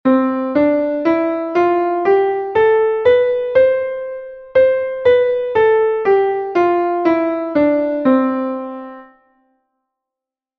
C-Dur-Tonleiter, aufwärts und abwärts
C-Dur-Tonleiter.mp3